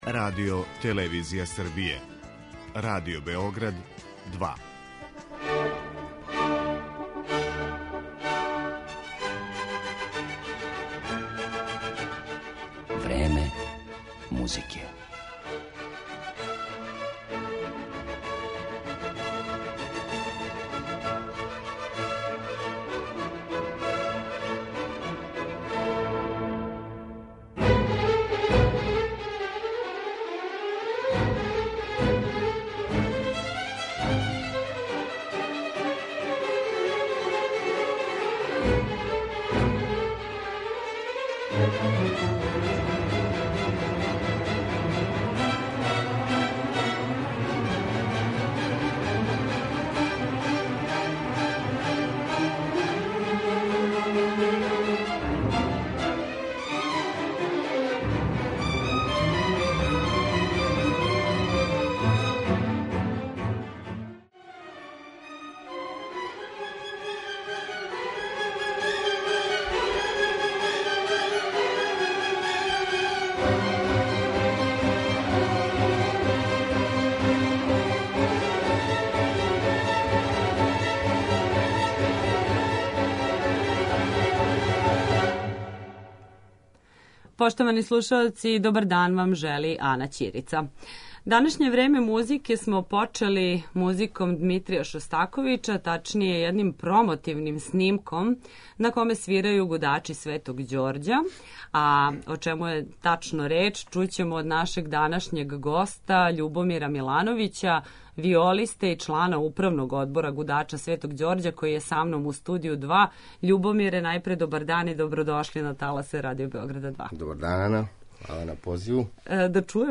Разговор о концерту Гудача Светог Ђорђа